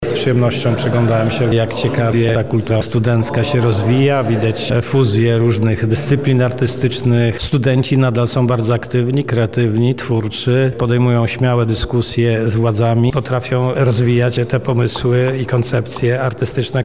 Swoimi odczuciami podzielił się również jeden z uczestników wydarzenia:
Uczestnik Forum
wywiad-uczestnik-forum.mp3